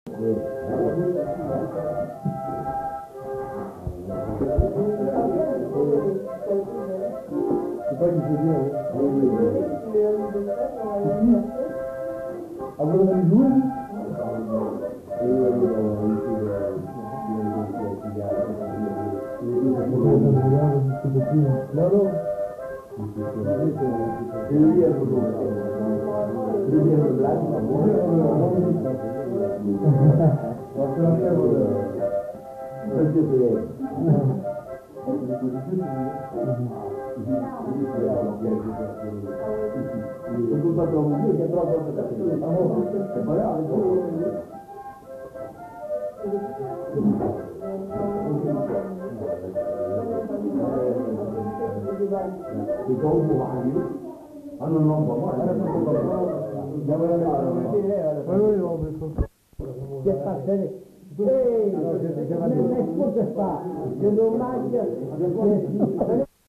Aire culturelle : Gabardan
Lieu : Estigarde
Genre : morceau instrumental
Instrument de musique : harmonica
Danse : valse
Notes consultables : La mélodie est couverte par la discussion.